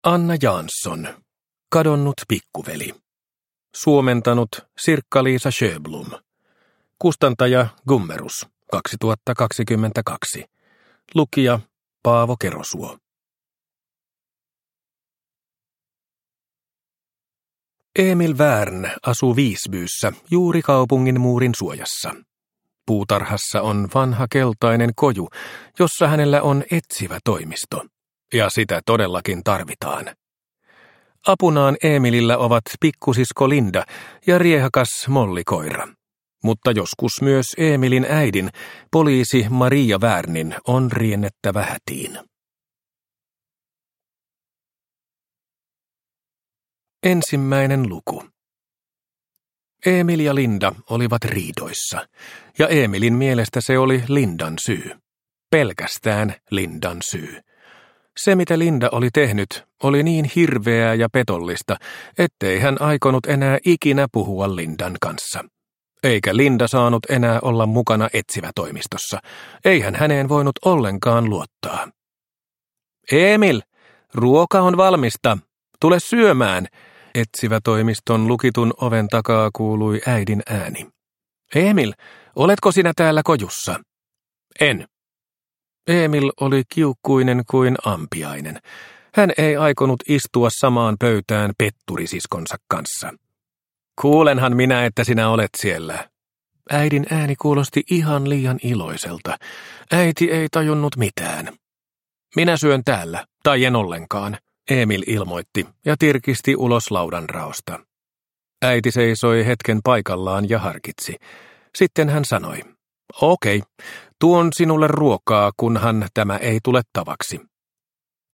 Kadonnut pikkuveli – Ljudbok – Laddas ner